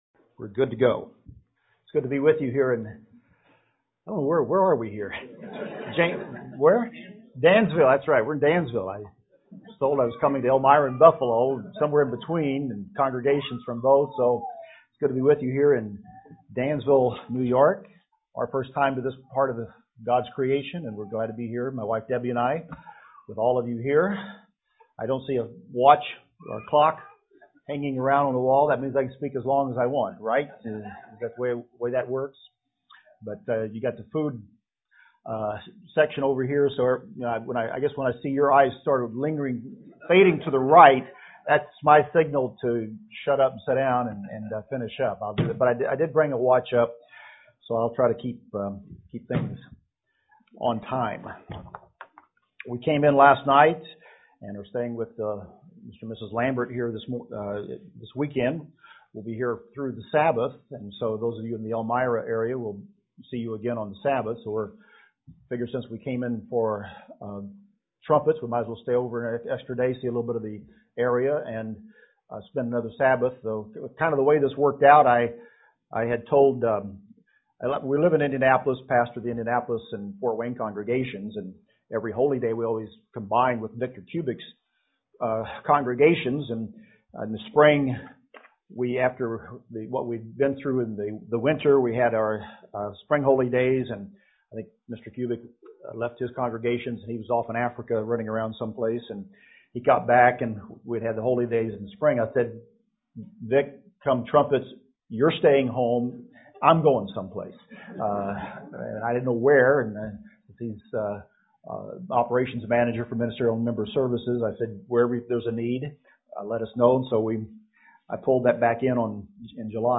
What is the resurrection of life and when it occurs UCG Sermon Transcript This transcript was generated by AI and may contain errors.